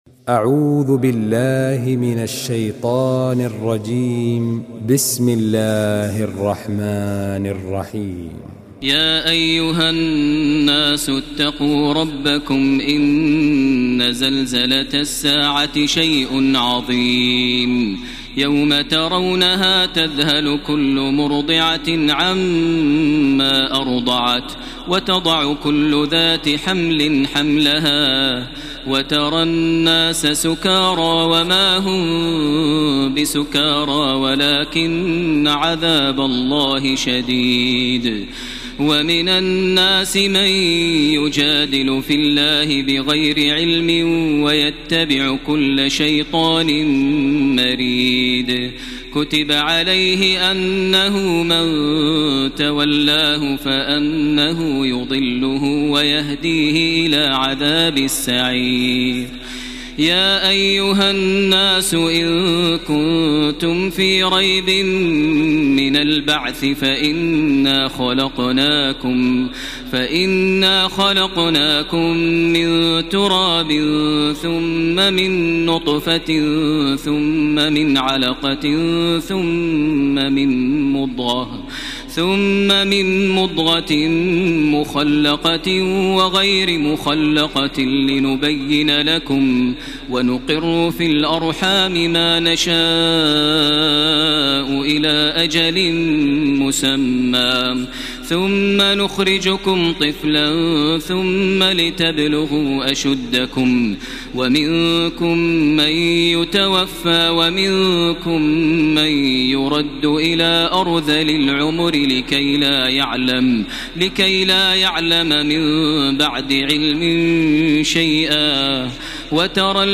تراويح الليلة السادسة عشر رمضان 1433هـ سورة الحج كاملة Taraweeh 16 st night Ramadan 1433H from Surah Al-Hajj > تراويح الحرم المكي عام 1433 🕋 > التراويح - تلاوات الحرمين